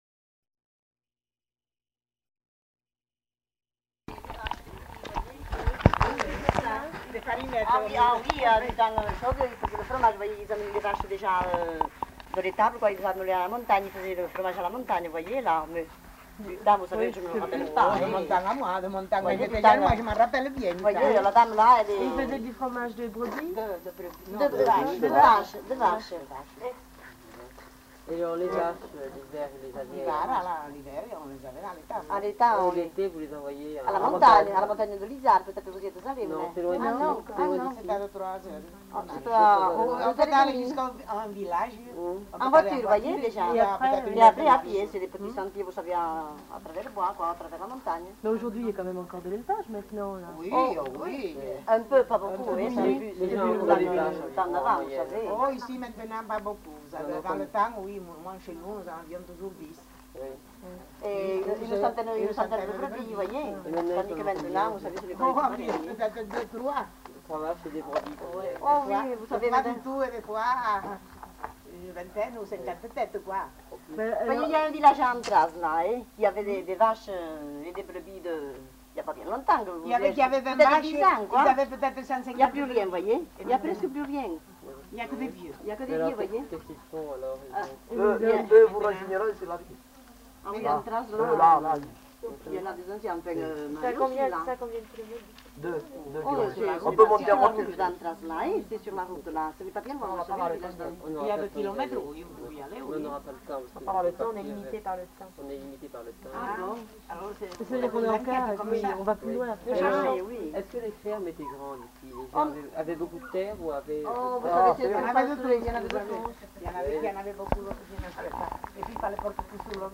Nature du document : enquête
Type de son : mono
Qualité technique : bon
Dans le cadre du stage Bigorre-Ariège 1967, un groupe de stagiaires réalise une enquête auprès de plusieurs habitants de Sentein. Il est tout d'abord question de travaux agricoles comme ceux de l'élevage et du travail aux champs, puis de l'intérieur domestique et de son mobilier, ainsi que de l'alimentation. L'enquête s'achève sur l'évocation du groupe folklorique Les Biroussans et la pratique de la danse telle que l'ont connue les témoins dans leur jeunesse.